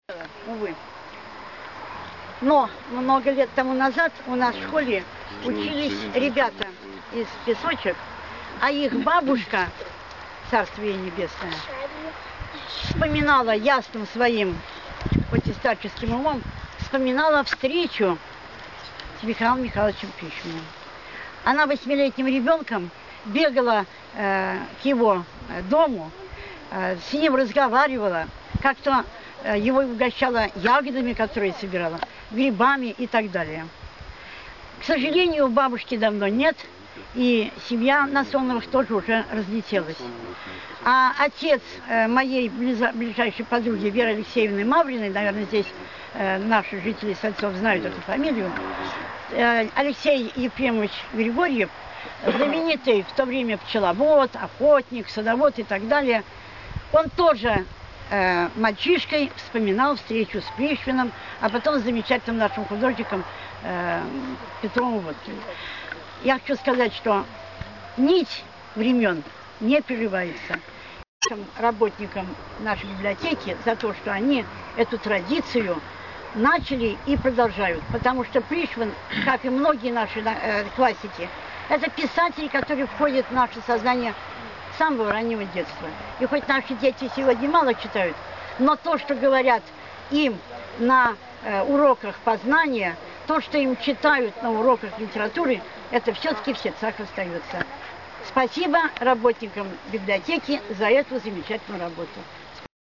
Прозвучали воспоминания о встречах местных жителей с Михаилом Пришвиным.